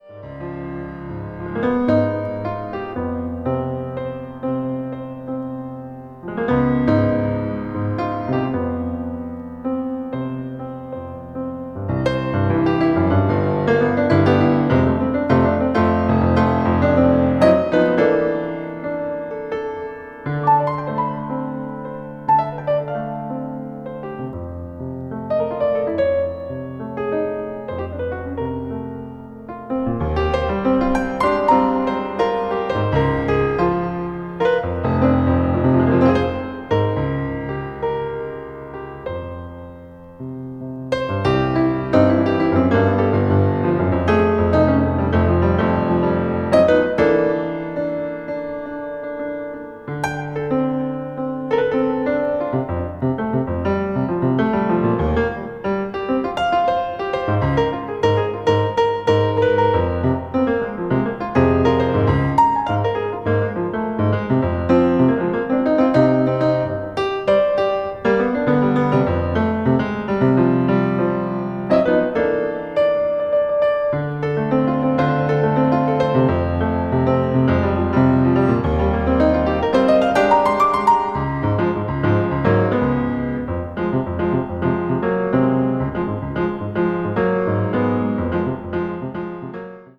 media : EX+/EX+(some slightly noises.)